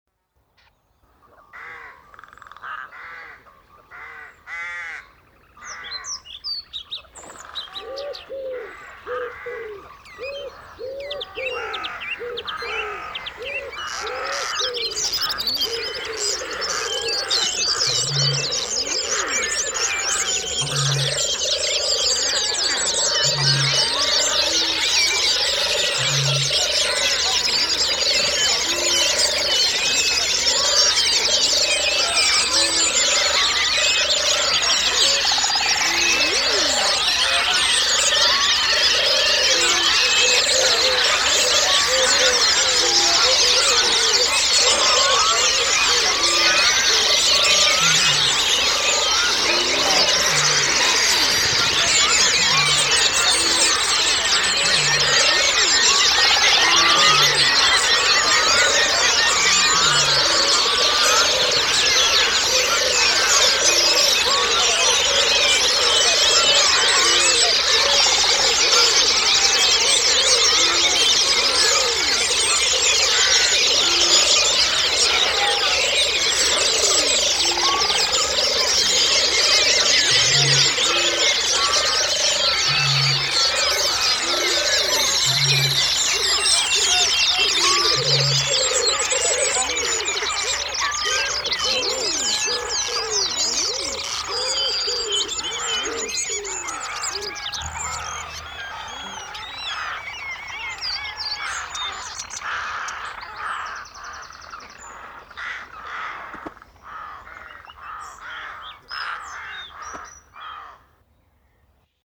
Songs and call of British Birds (172 species)
This audio track simultaneously plays all the British bird's songs.